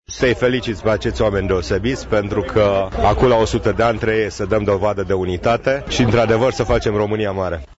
Printre cei care au fost alături de românii veniți, precum înaintașii lor, cu căruțele, s-a aflat și primarul comunei Feldioara, Sorin Taus: